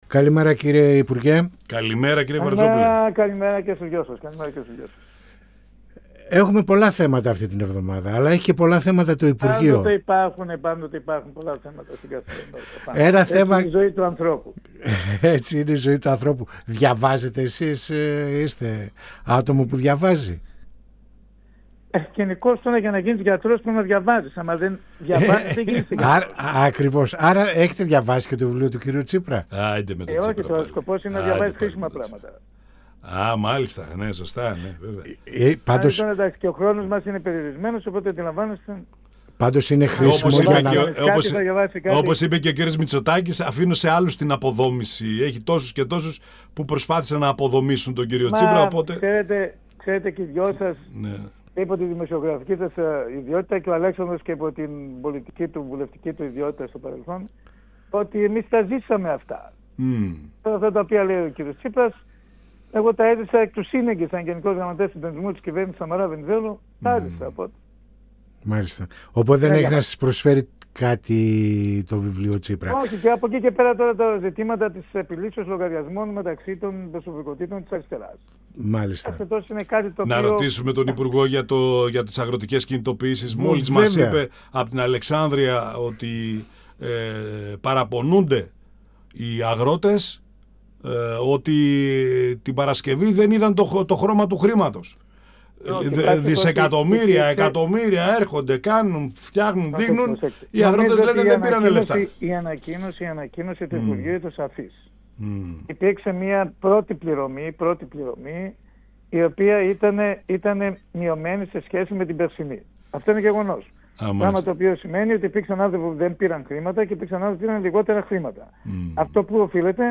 Στις αγροτικές κινητοποιήσεις και τα αγροτικά μπλόκα, στο σκάνδαλο του ΟΠΕΚΕΠΕ και στις πρωτοβουλίες του Υπουργείου Υγείας για να αντιμετωπιστεί η μάστιγα του αλκοολισμού αναφέρθηκε ο Υφυπουργός Υγείας και Βουλευτής Β΄ Θεσσαλονίκης της Ν.Δ. Δημήτρης Βαρτζόπουλος, μιλώντας στην εκπομπή «Πανόραμα Επικαιρότητας» του 102FM της ΕΡΤ3.
Συνεντεύξεις